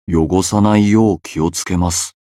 觉醒语音 汚さないよう気をつけます 媒体文件:missionchara_voice_514.mp3